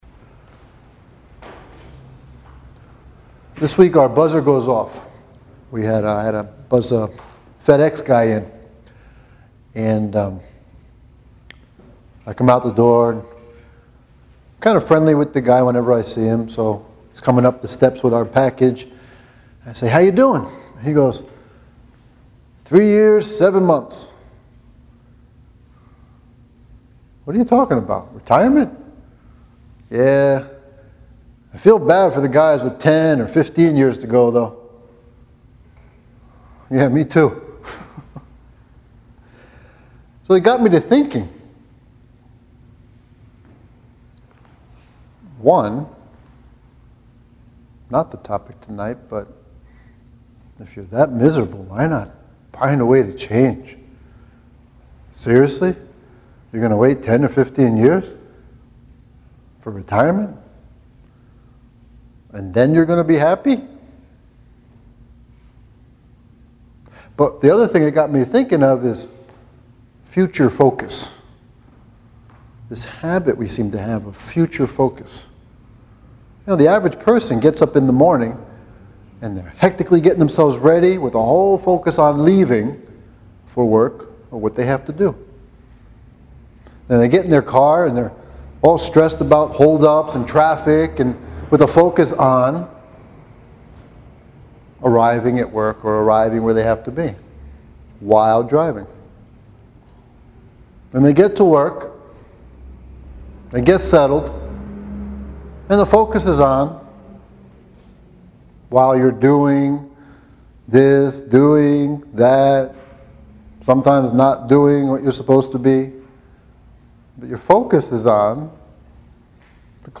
Then this talk is the wake up call you need. It’s weaved with intereresting stories that bring home the importance of seeking out the joy in our lives rather than focus on the suffering.